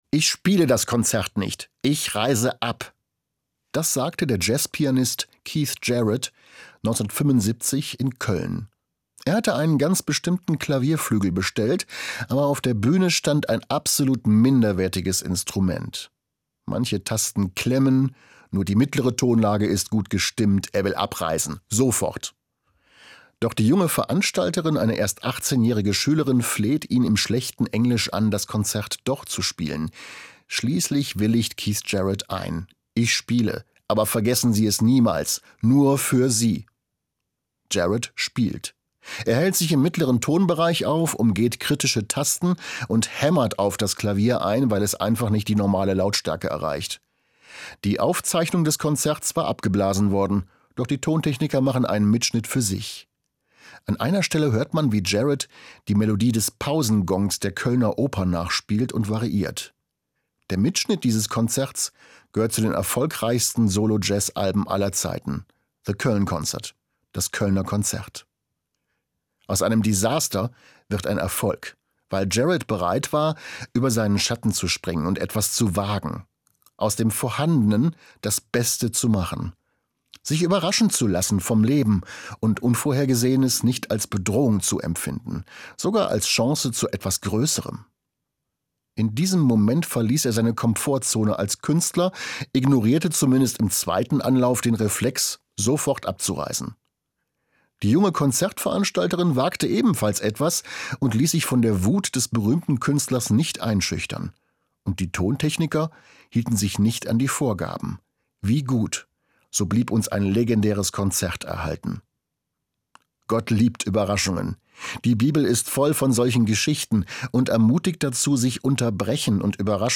Pastor, Hanau